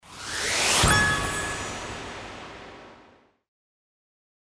FX-1563-CHIMED-WIPE
FX-1563-CHIMED-WIPE.mp3